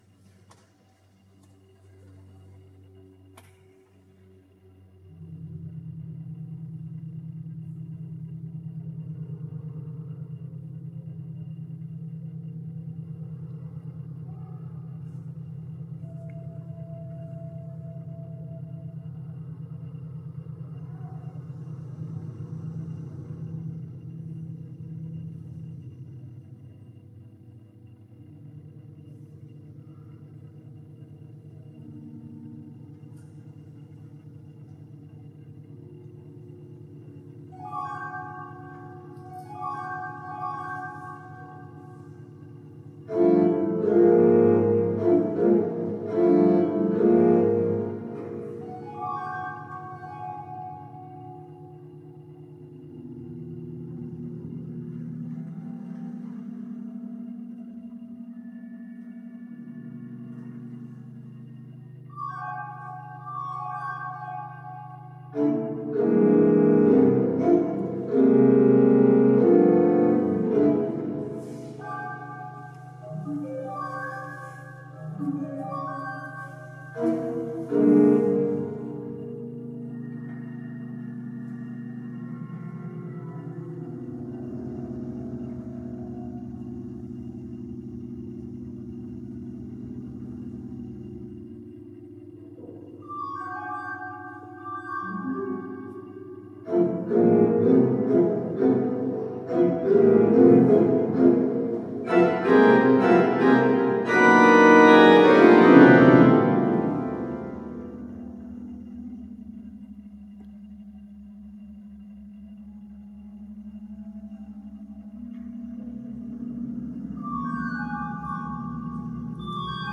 en orgelkonsert i Stora Tuna kyrka
Blev nyfiken på kompositören eftersom det var lite udda musik.
Har bara en halvkass ljudupptagning som jag gjort själv med digitalkameran.
Det som rasslar på inspelningen är den optiska bildstabiliseringsmekanismen som tyvärr inte går att stänga av vid videoupptagning i Panasonic FZ1000.